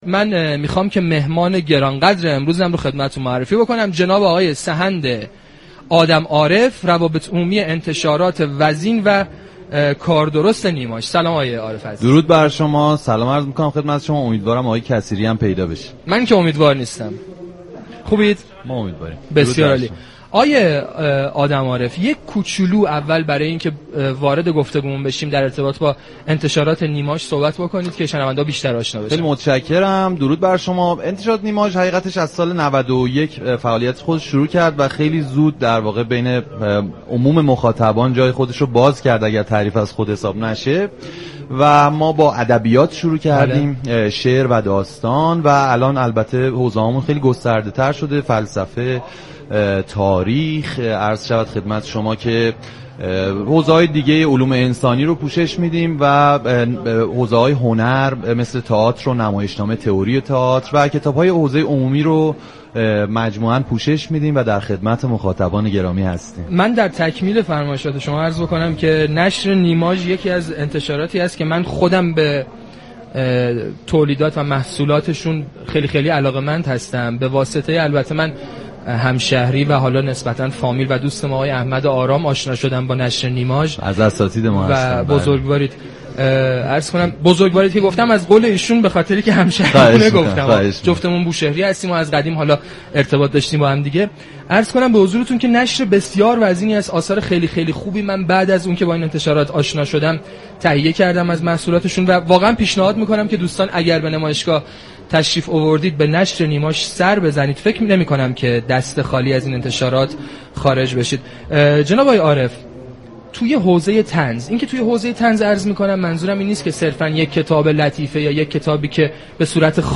به گزارش روابط عمومی رادیو صبا، ویژه برنامه «دیباچه» به مناسب ایام برگزاری نمایشگاه بین الملی كتاب تهران با هدف پوشش اخبار و رویداد های نمایشگاه از محل مصلی امام خمینی (ره) همراه مخاطبان می شود.
این برنامه با بخش های متنوع «گفتگو با ناشران و مولفان، معرفی طنز پردازان بزرگ، مسابقه نویسندگی و اجرای طنز» به صورت زنده تقدیم شنوندگان میشود.
در ادامه این گفتگو را می شونیم